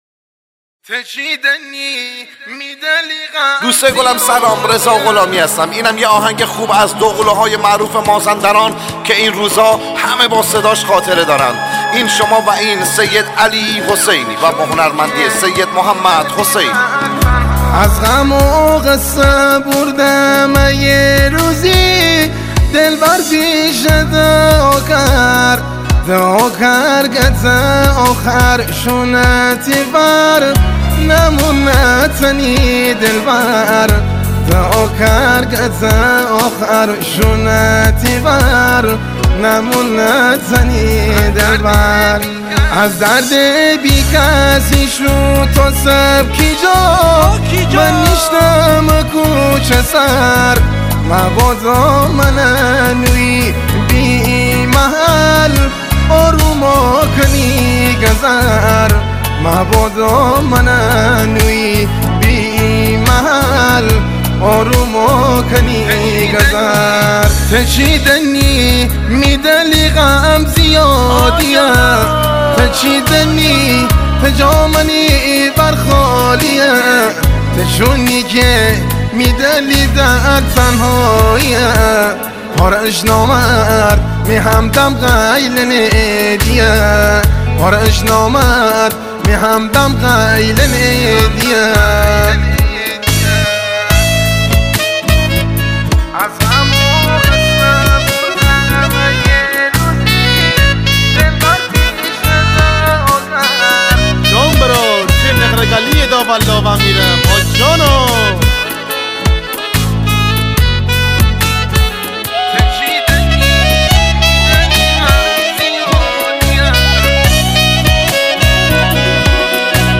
آهنگ شمالی
آهنگ گیلانی
آهنگ های شاد شمالی